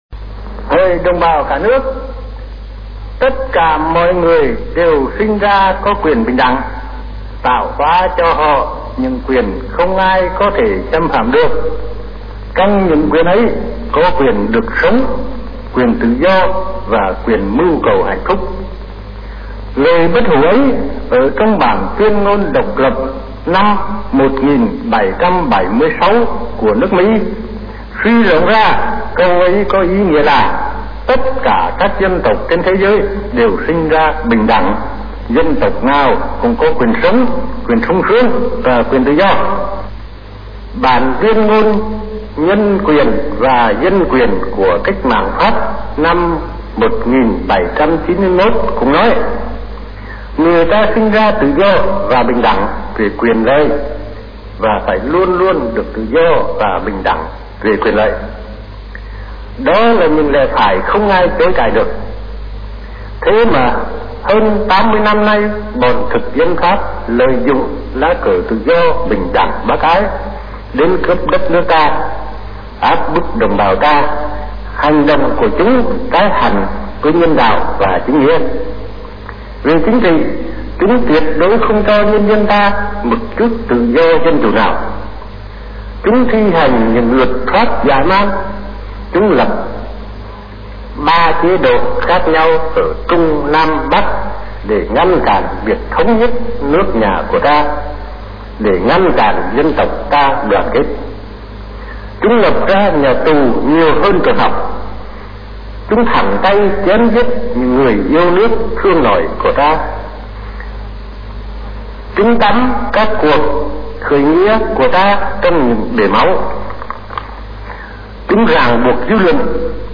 On September 2, 1945, at Ba Ðình Square, President Hồ Chí Minh sonorously read the Declaration of Independence of the Democratic Republic of Vietnam.